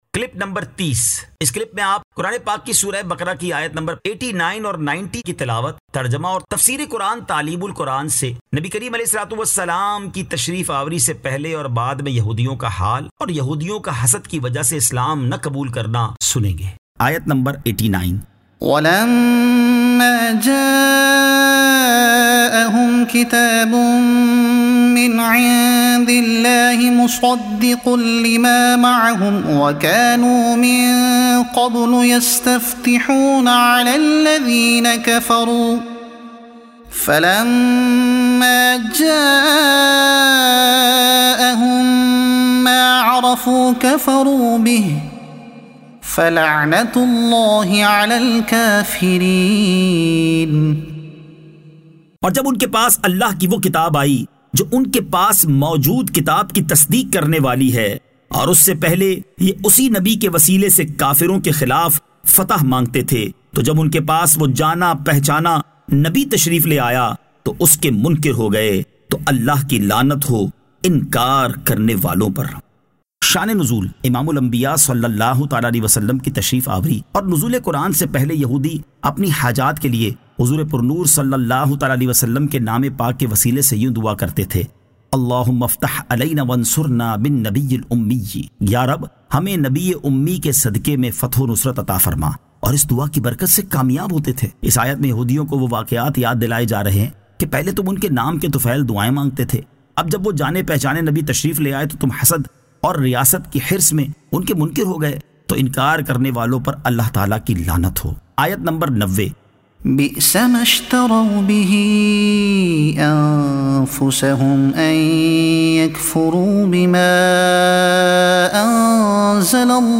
Surah Al-Baqara Ayat 89 To 90 Tilawat , Tarjuma , Tafseer e Taleem ul Quran